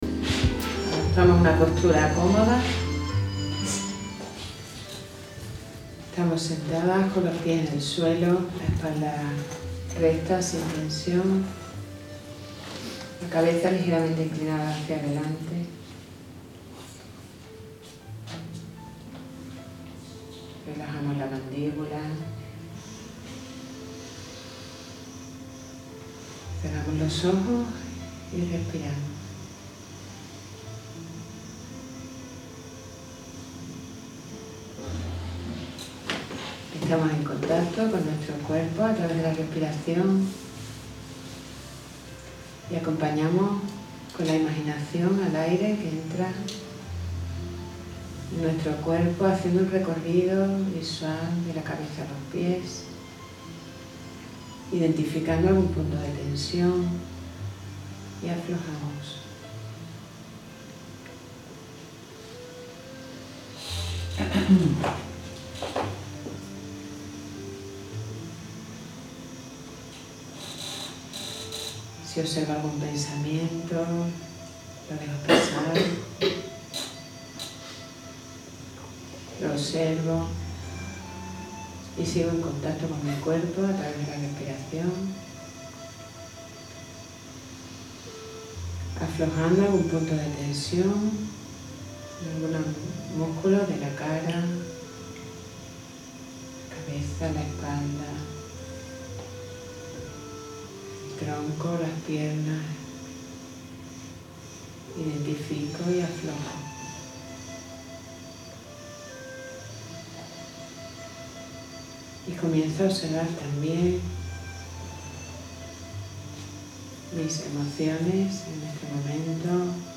1-Meditación-La-pareja-consciente-M3-2.mp3